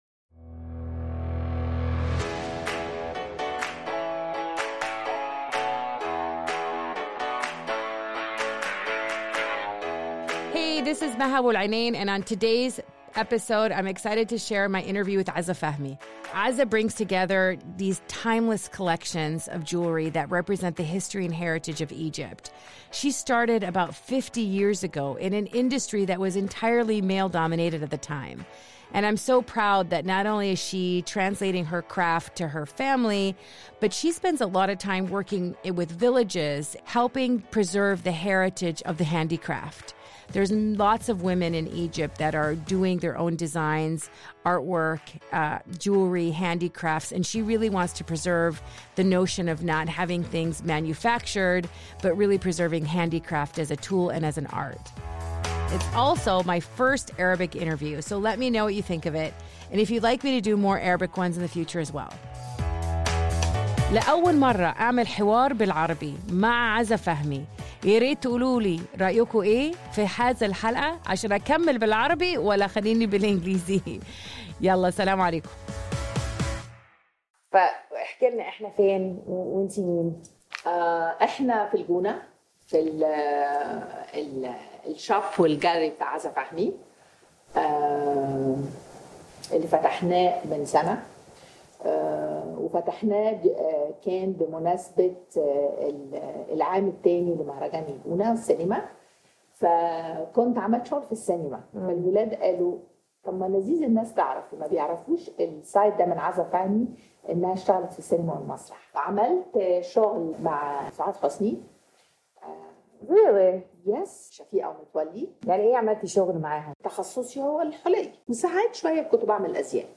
Excited to share my first Arabic interview with the jewel of Egypt, Azza Fahmy.